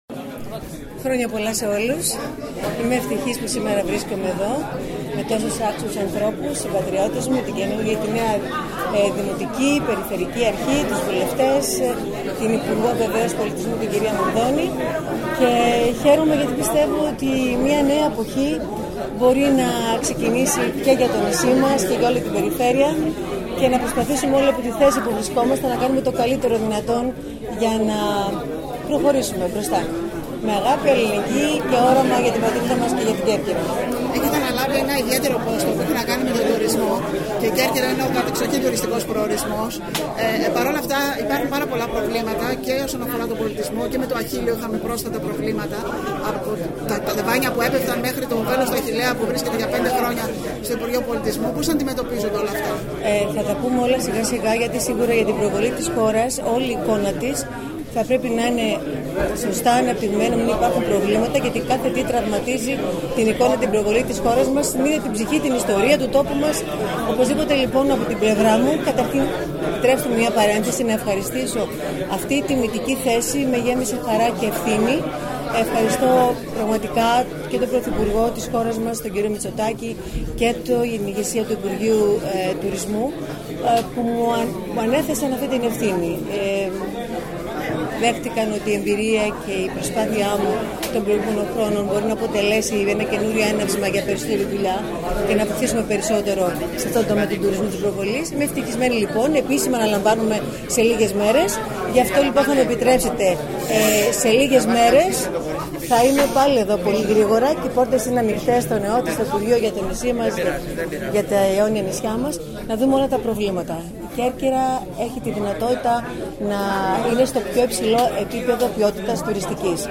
Κέρκυρα: Δηλώσεις πολιτικών και αυτοδιοικητικών (audio)
Η νέα πρόεδρος του ΕΟΤ Άντζελα Γκερέκου σε δηλώσεις της μετά την λιτανεία του Αγίου Σπυρίδωνος ευχήθηκε σε όλους τους κερκυραίους χρόνια πολλά για την σημερινή εορτή ενώ αναφορικά με τα θέματα που αντιμετωπίζει η Κέρκυρα είπε χαρακτηριστικά ότι «σε σύντομο χρονικό διάστημα θα εξεταστούν όλα τα ζητήματα του τουρισμού που απασχολούν την Κέρκυρα προκειμένου  το νησί να βρει και πάλι το βηματισμό του και να  ανέβει στο επίπεδο του του αξίζει».